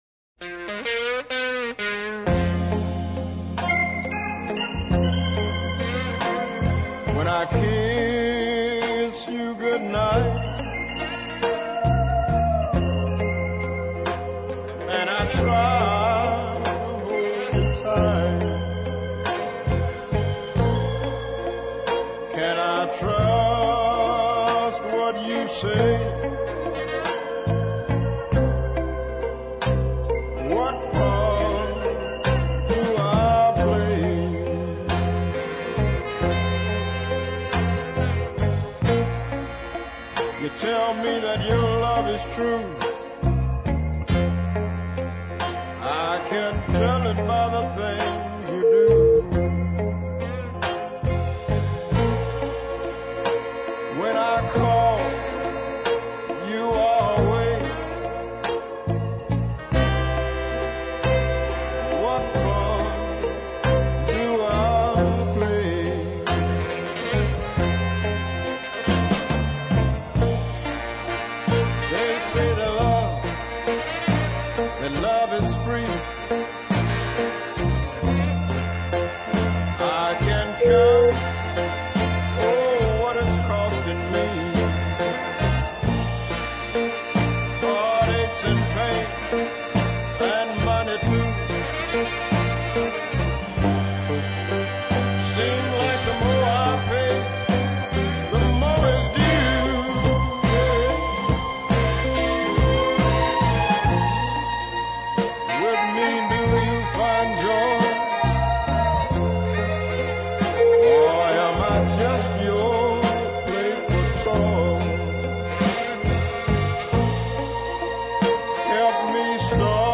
rich, dark baritone voice
a lovely minor-keyed ballad